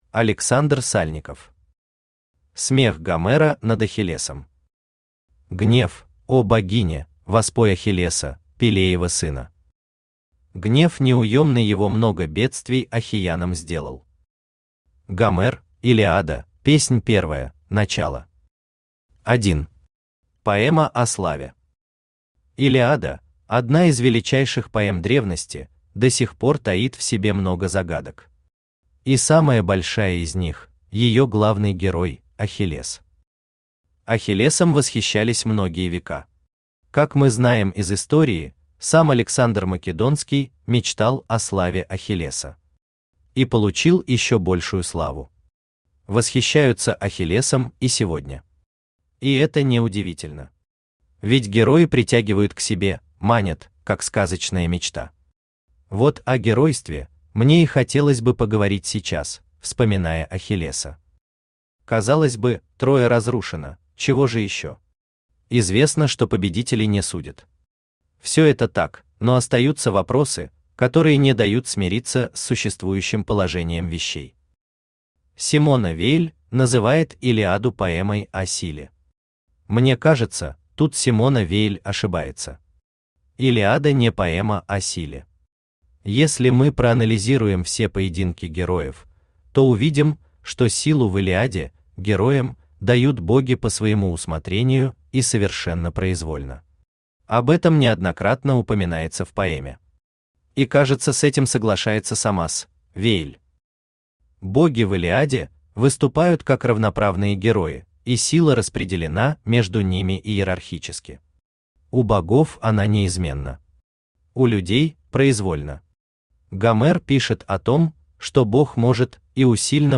Аудиокнига Смех Гомера над Ахиллесом | Библиотека аудиокниг
Aудиокнига Смех Гомера над Ахиллесом Автор Александр Аркадьевич Сальников Читает аудиокнигу Авточтец ЛитРес.